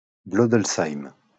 Blodelsheim (French pronunciation: [blodəlsaim]